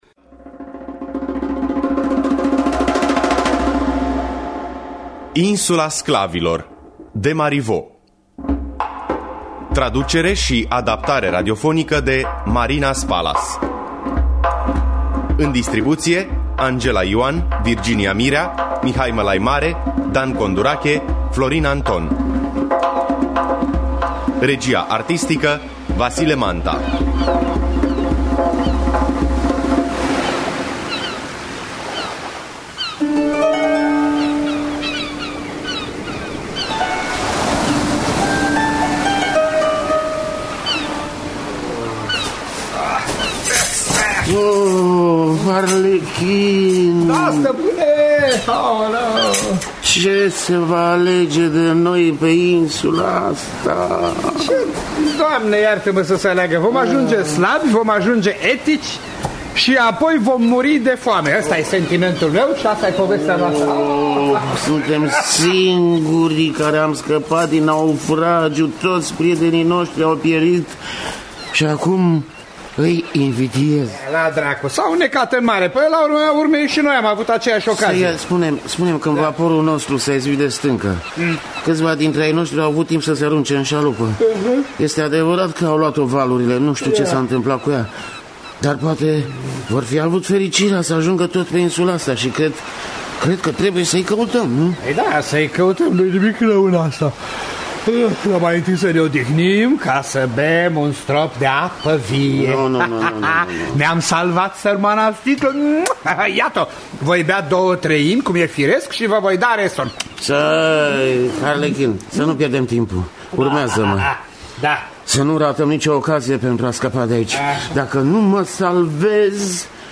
Traducerea şi adaptarea radiofonică